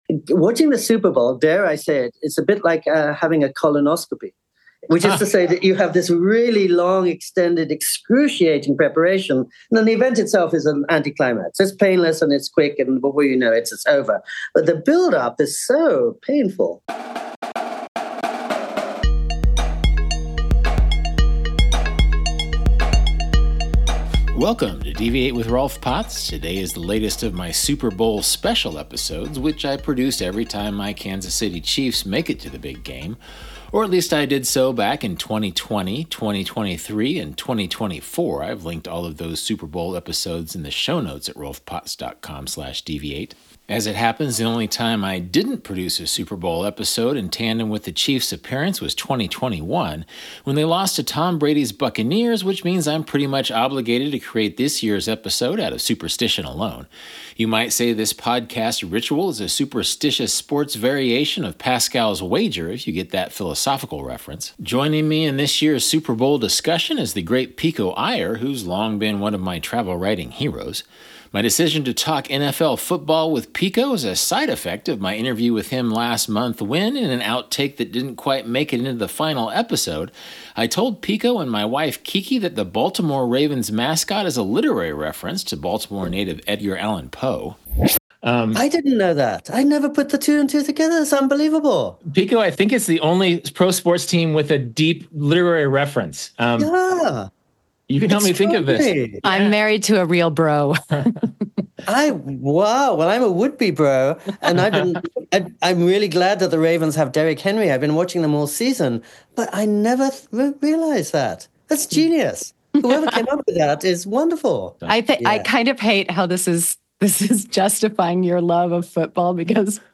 A travel writers' Super Bowl special: Pico Iyer and Rolf discuss NFL football from the global perspective